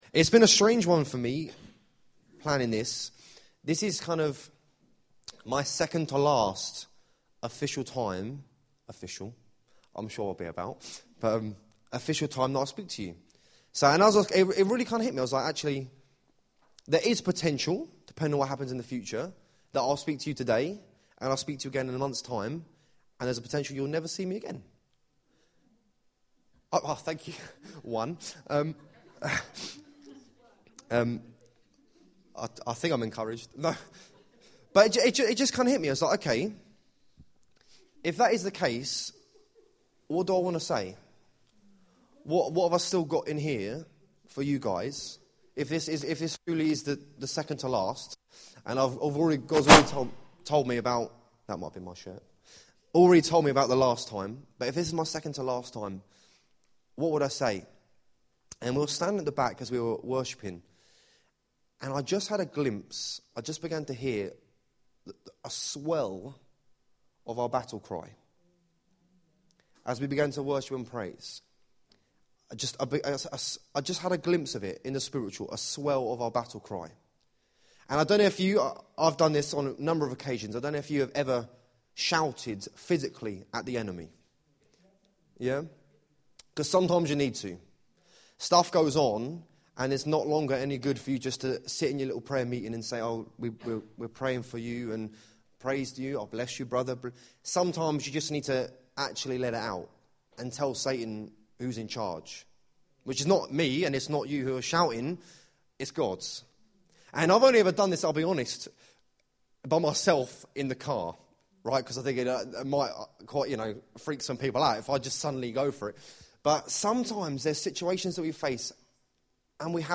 Joshua 5:13-15 Service Type: Sunday Morning « Joshua